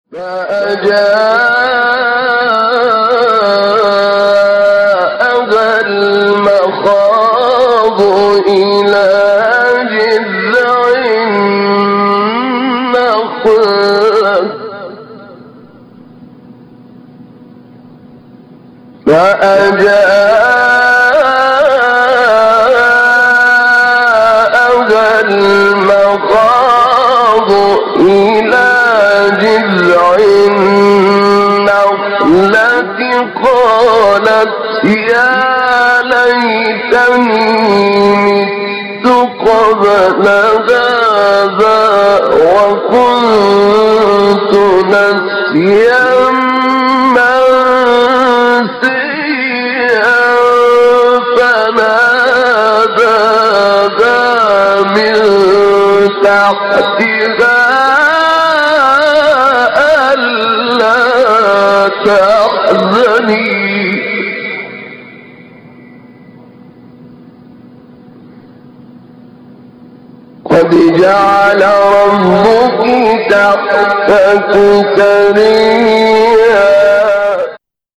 من هو هذا القارئ :